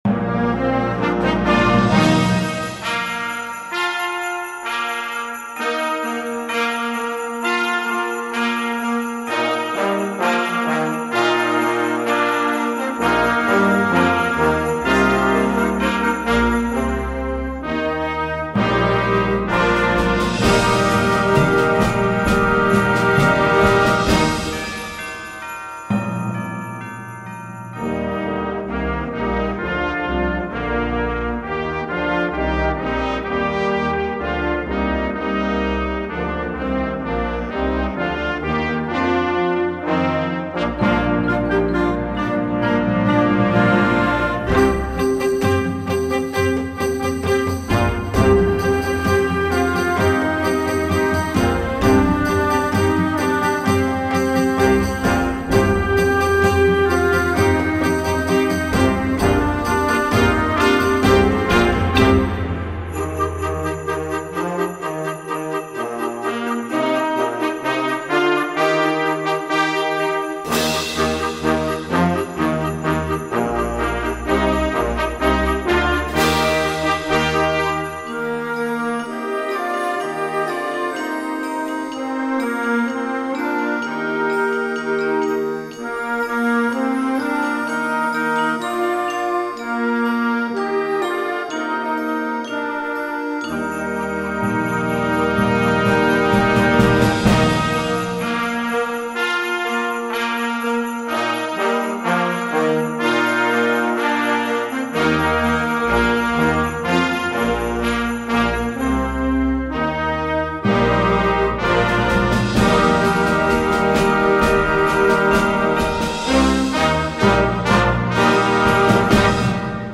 Gattung: Jugendwerk Weihnachten
Besetzung: Blasorchester